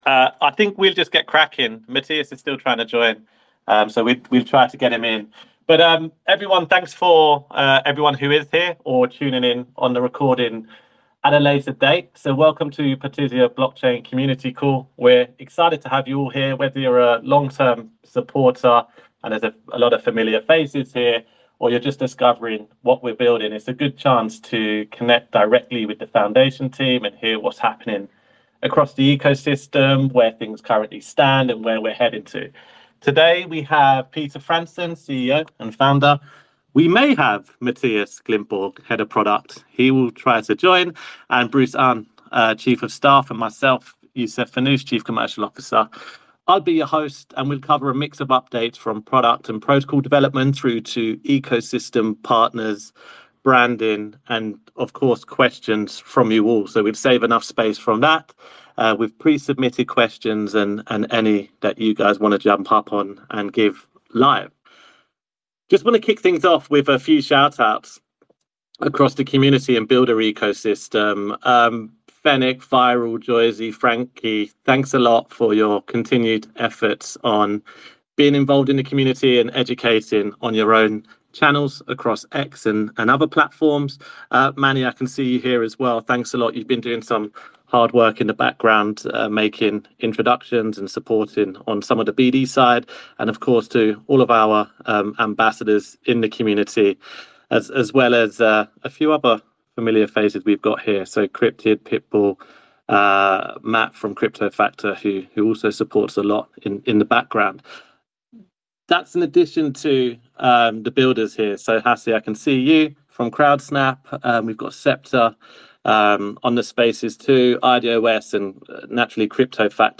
Listen to All Things Partisia Blockchain AMA – 28th May, 2025